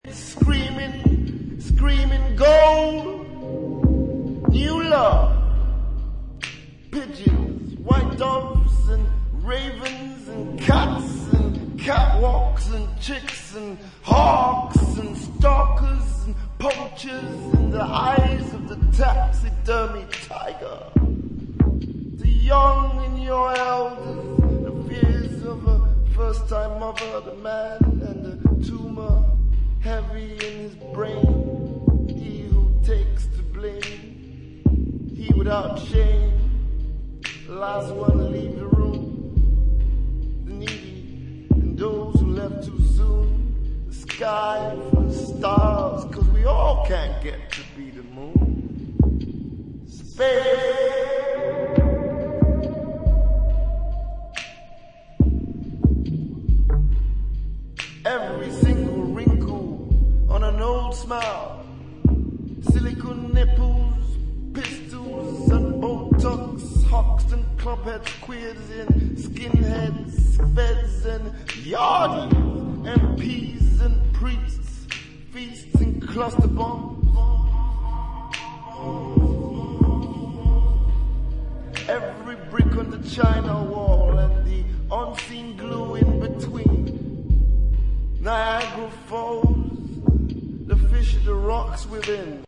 sound stripped back to its dark exoskeleton
Electronix Bass